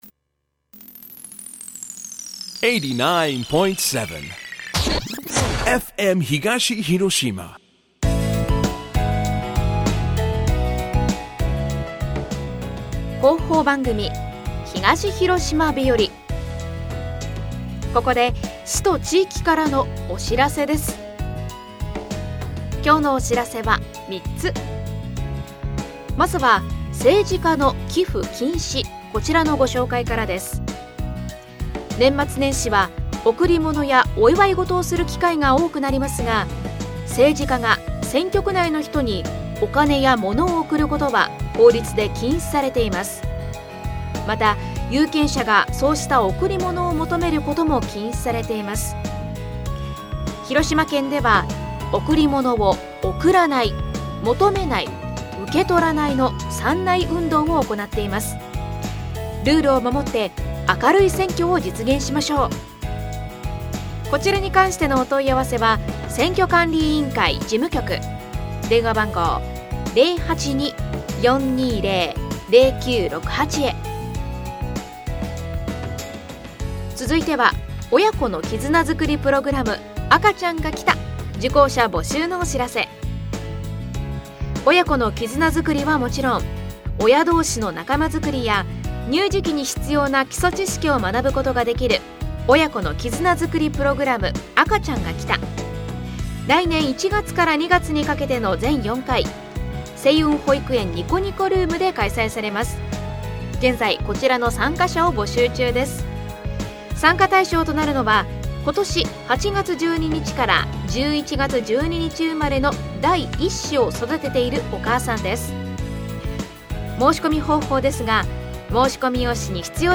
広報番組「東広島日和」です。今日は「政治家の寄附禁止」「親子の絆づくりプログラム赤ちゃんがきた」「2021年版広島県民手帳を販売しています」についてです。